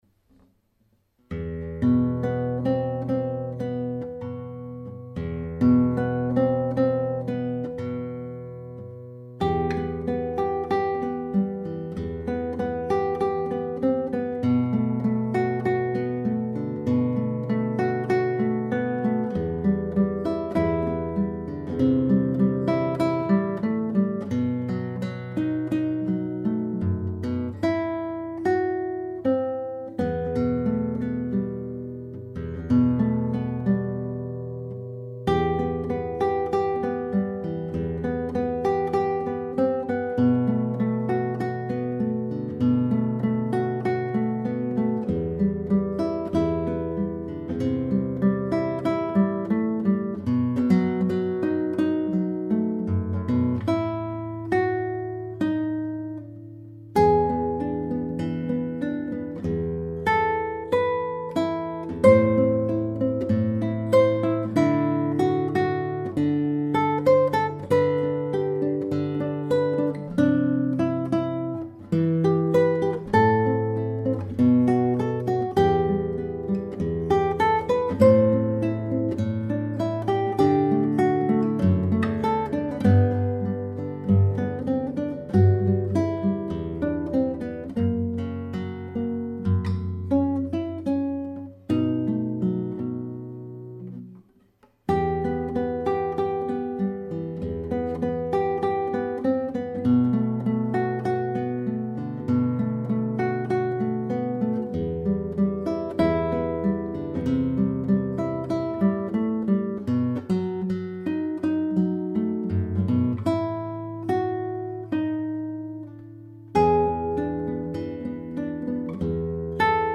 古典吉他视听
好听，节奏再准确些更好
很好了，只是可能稍微生疏了吧？再恢复恢复肯定会更动听了！